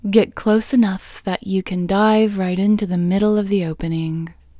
IN – the Second Way – English Female 12